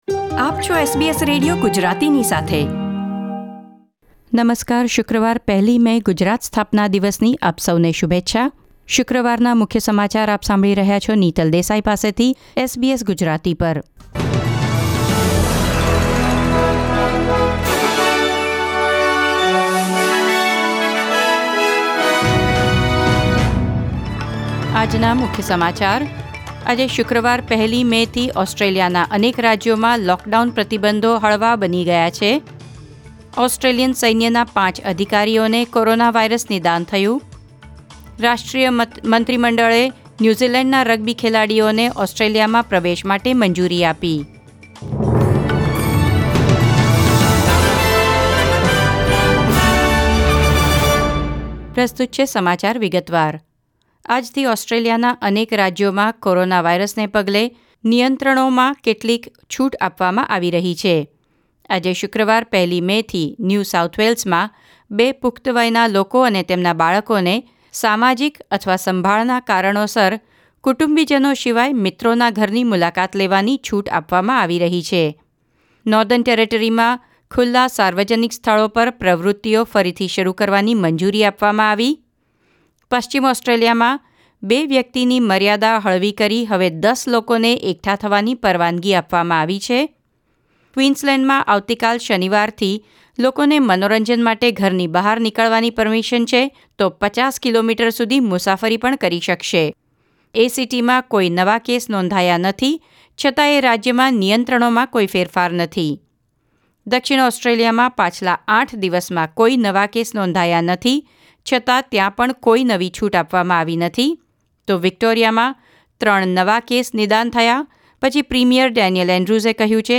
SBS Gujarati News Bulletin 1 May 2020